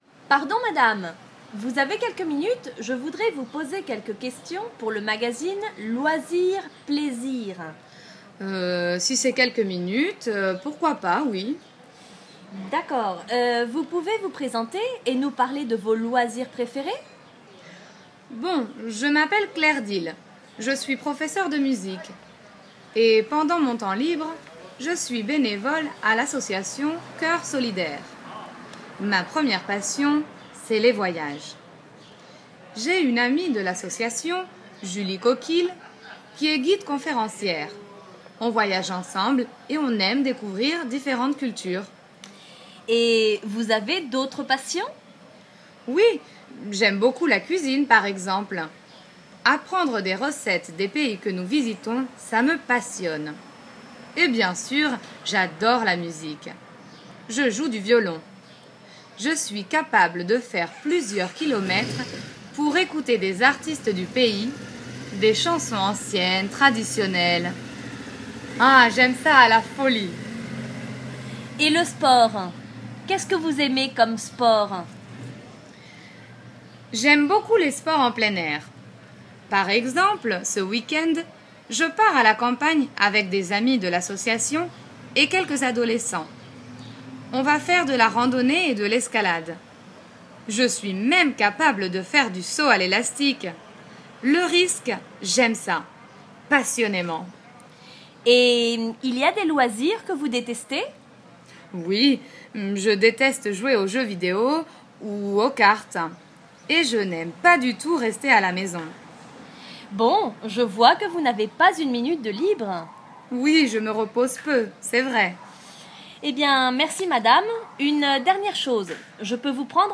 A continuación, te presentamos una entrevista para la revista Loisirs-Plaisirs.